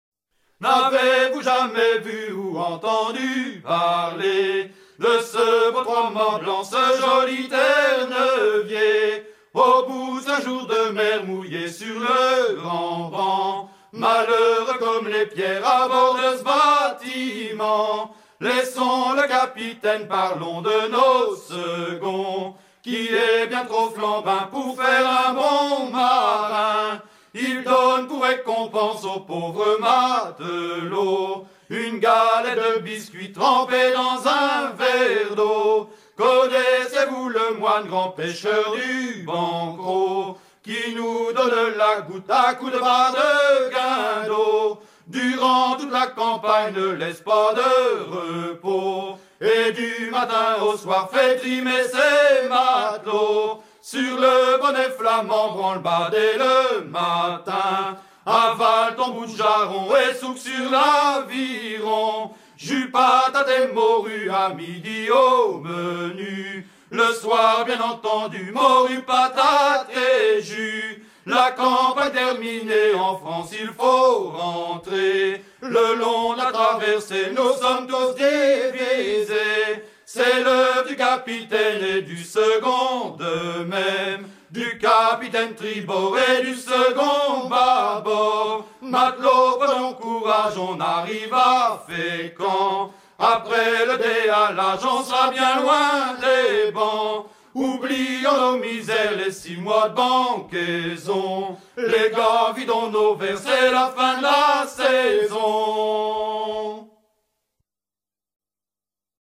circonstance : maritimes